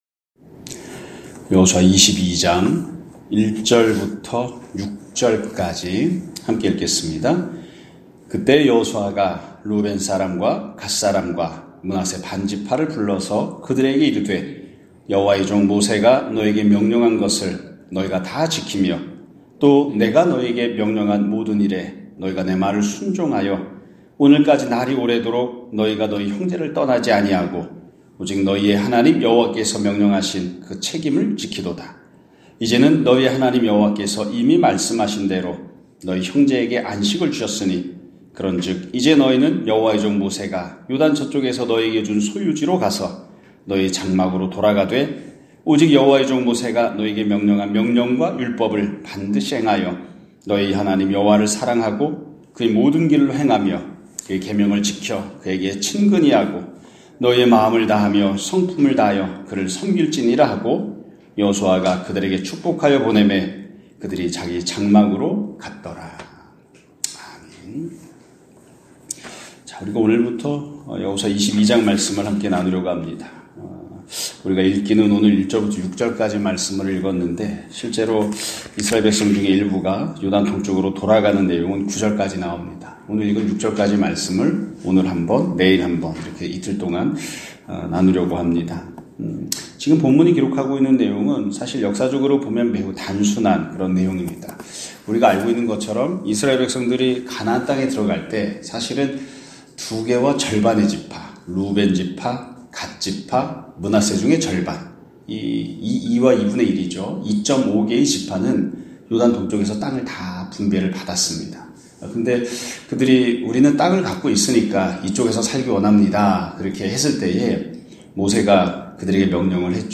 2025년 2월 5일(수 요일) <아침예배> 설교입니다.